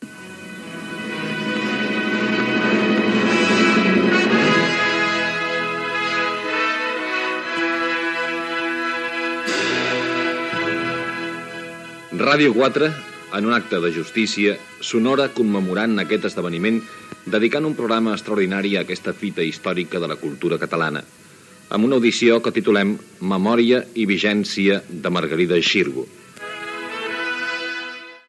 Identificació del programa en el seu inici
FM